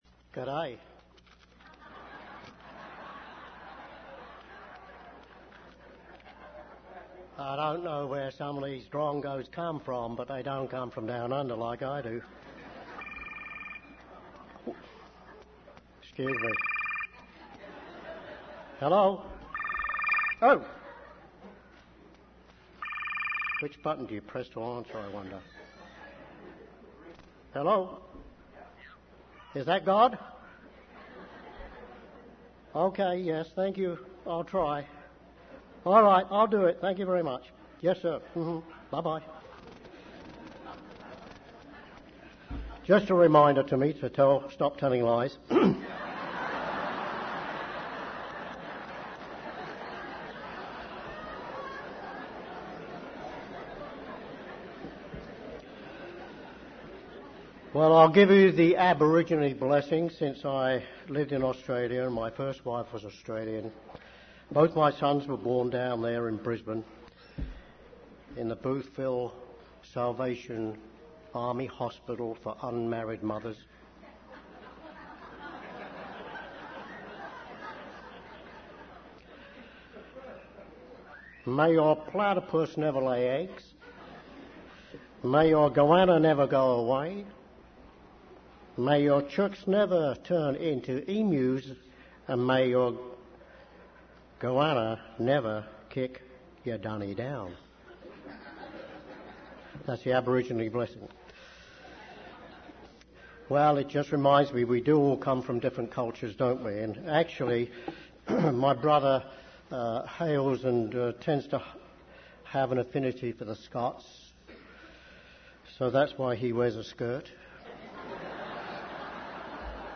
This sermon was given at the Jekyll Island, Georgia 2005 Feast site.